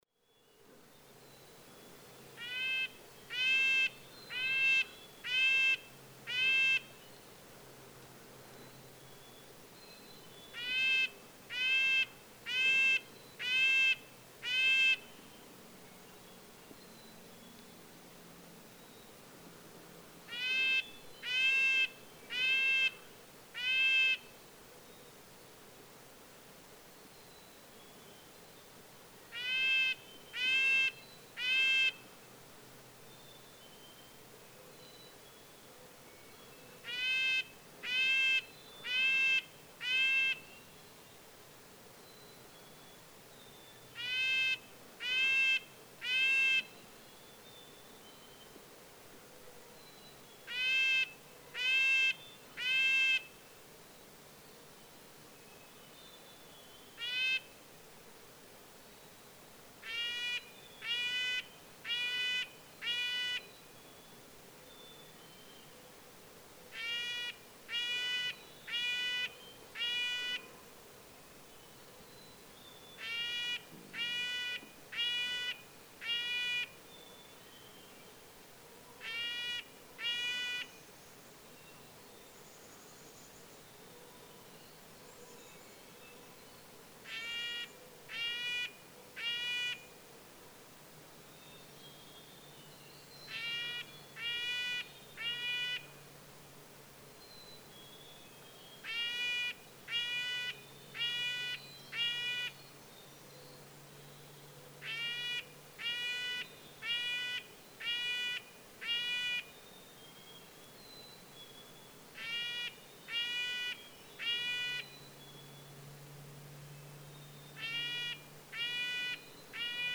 Red-breasted nuthatch
His songs are a simple series of nasal yank notes, easily overlooked in the morning chorus.
♫689. In this selection, believed to be from the same male ten days later, he lowers the pitch at the one minute mark, but the rate stays pretty much the same. The first series of yank songs (call it C) is different from those recorded ten days earlier (A), whereas the second series is the same as B above.
Rattlesnake Gutter, North Leverett, Massachusetts.
689_Red-breasted_Nuthatch.mp3